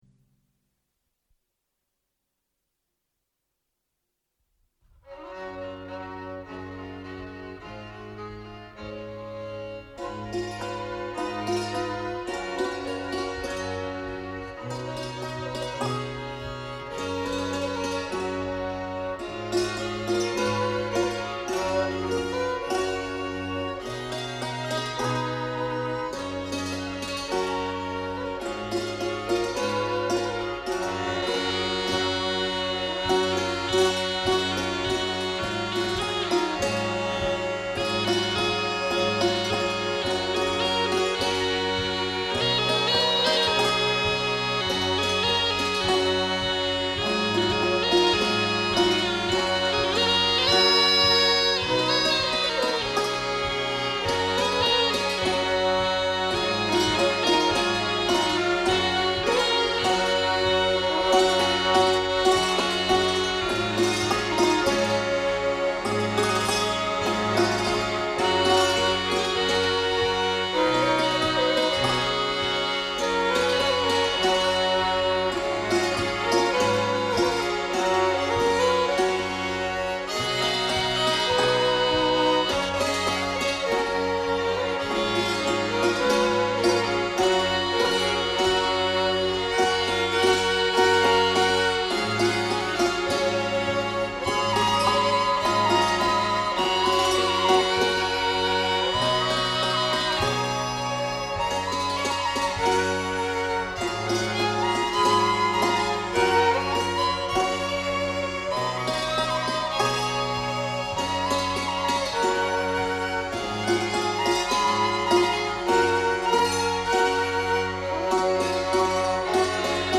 Paired dances